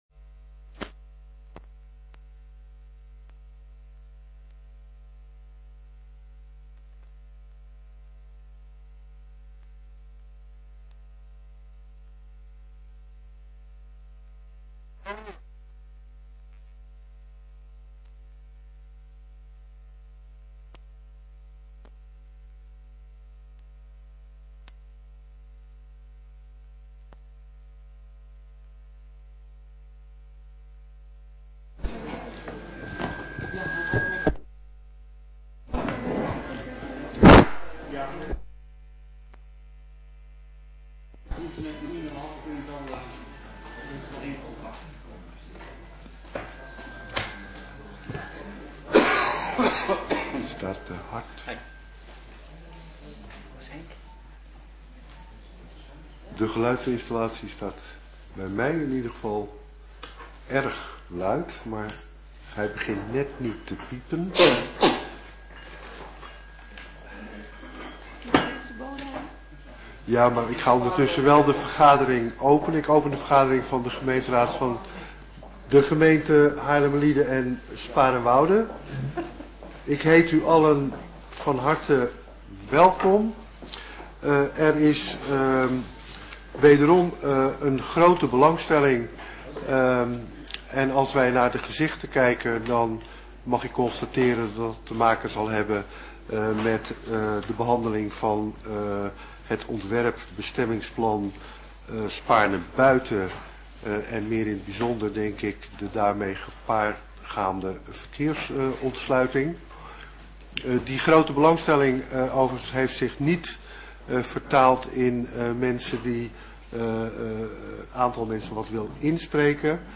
Begrotingsraad
Locatie: Raadzaal